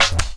Pickup 04.wav